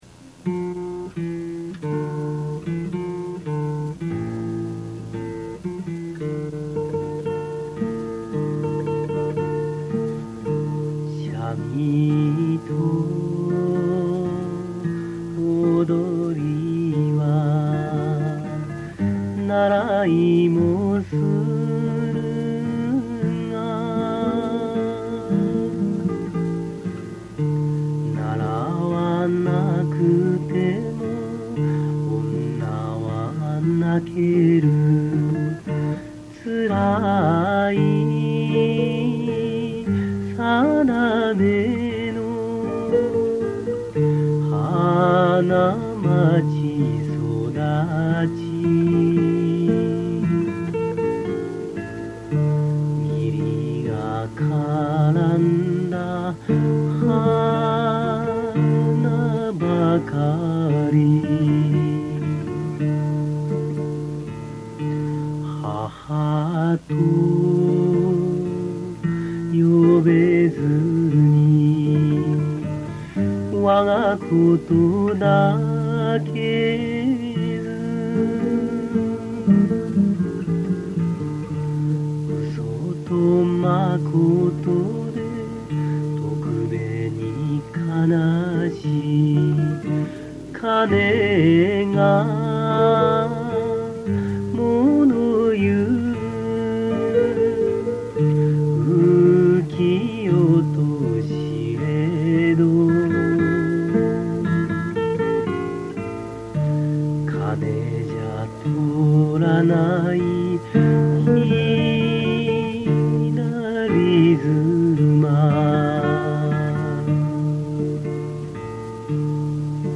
２００６．３．６アップ　　これも２０歳代後半の声です。